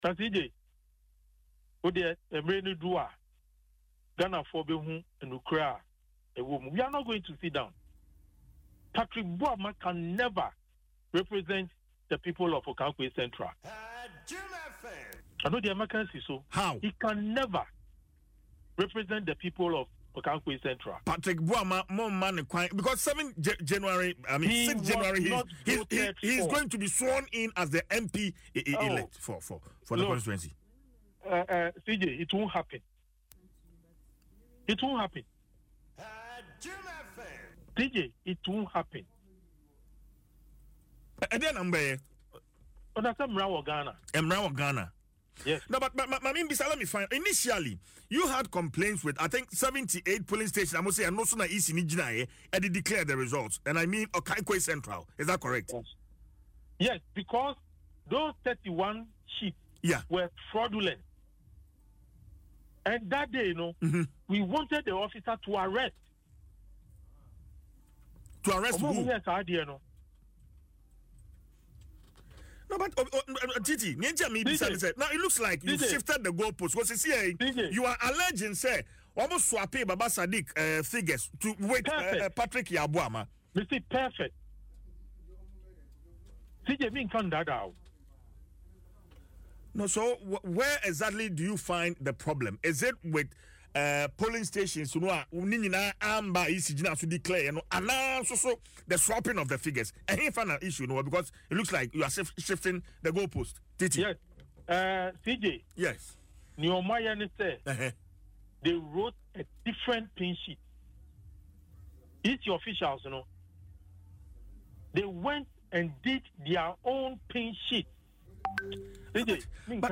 Speaking on Adom FM’s morning show Dwaso Nsem Monday, the former Ablekuma Central MP declared the NDC will not accept the re-collation and declaration.